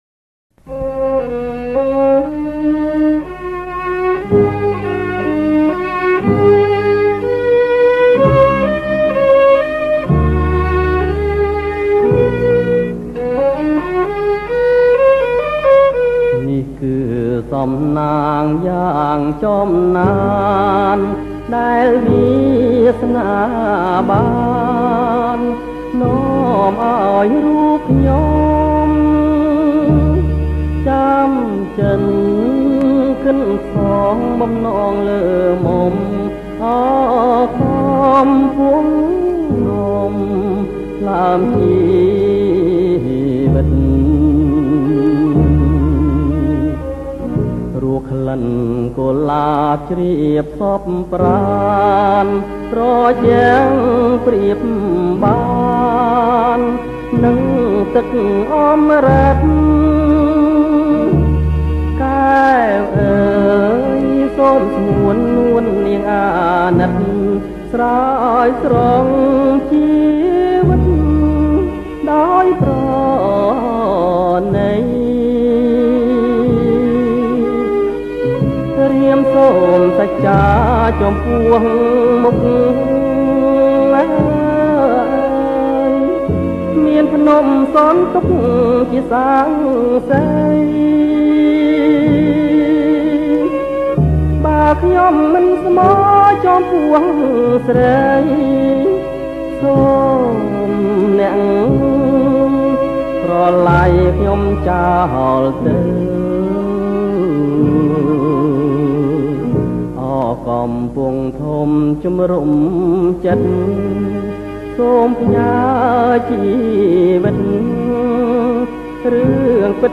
ប្រគំជាចង្វាក់ Slow